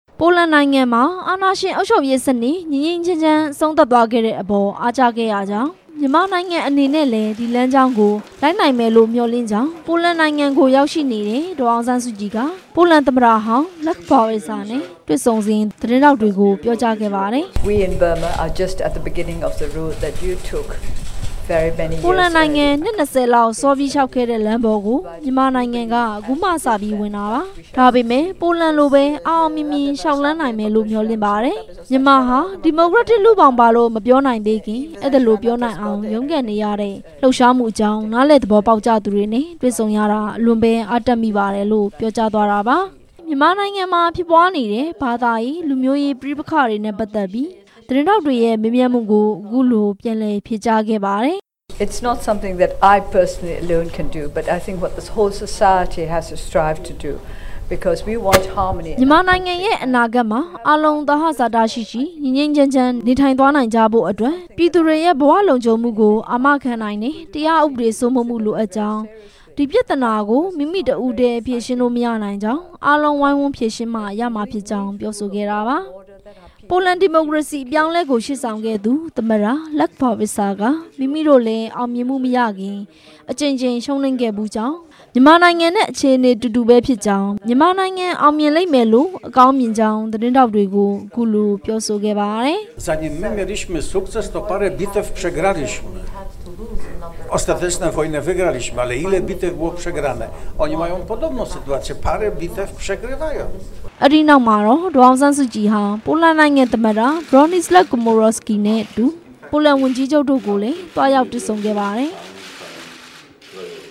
ဒေါ်အောင်ဆန်းစုကြည်ရဲ့ ပြောကြားချက်များ
ပိုလန်နိုင်ငံရောက်နေစဉ် သတင်းထောက်တွေရဲ့အမေး ကို ဒေါ်အောင်ဆန်းစုကြည်က အခုလို ဖြေဆိုလိုက် တာပါ။